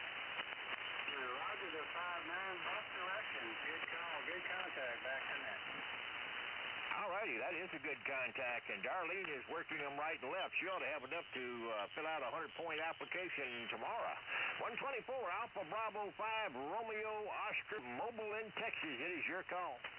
40 Meter SSB. Note the two different stations and the different signal strength each has.